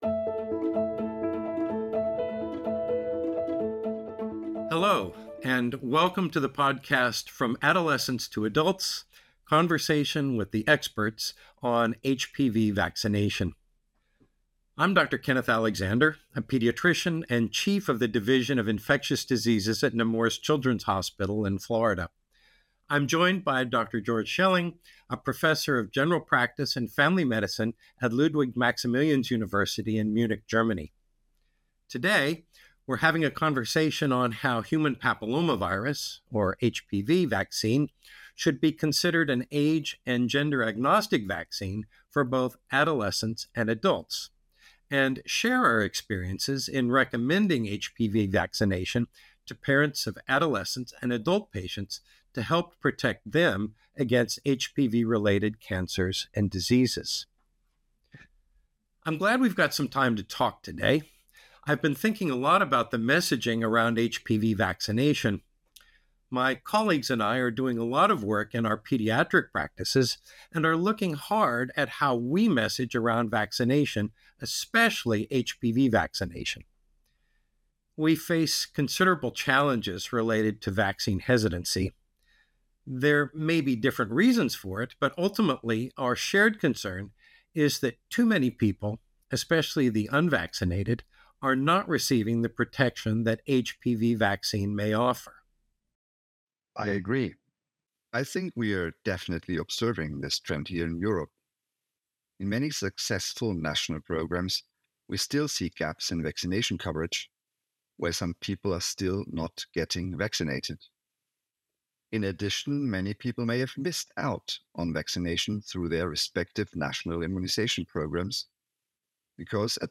From Adolescents to Adults: Conversation With the Experts on HPV Vaccination
Our guest speakers provide information and insight into the following areas:
From-Adolescents-to-Adults-Conversation-with-the-Experts-on-HPV-Vaccination.mp3